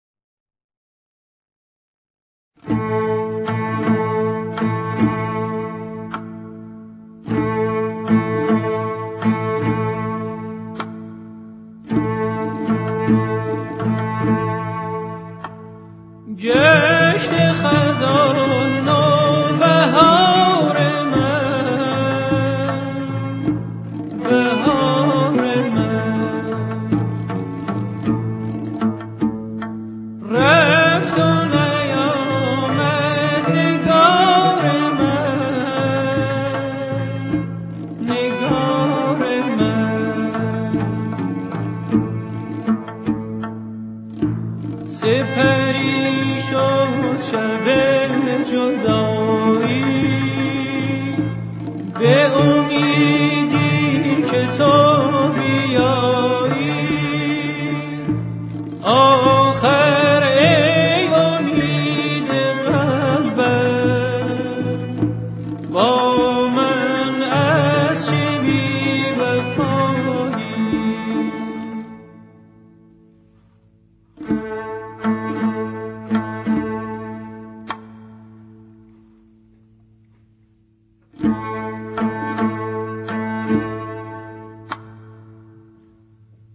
کارائوکی (آهنگ خالی مخصوص خوانندگی)